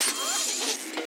SERVO SE01.wav